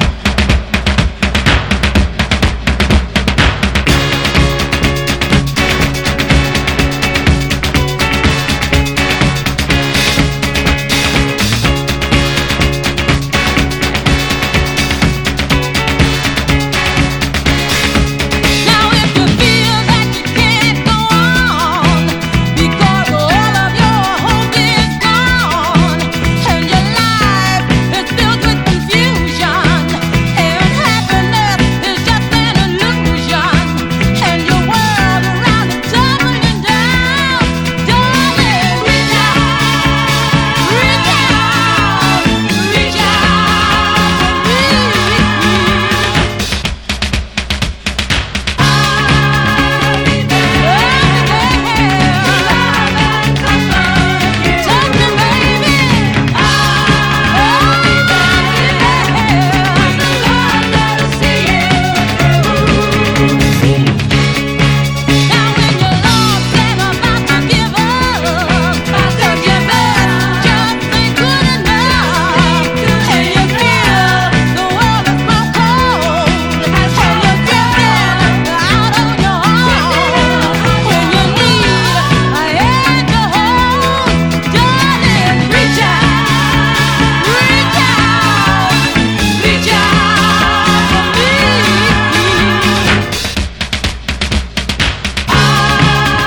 SOUL / SOUL / FREE SOUL / DISCO / DANCE CLASSIC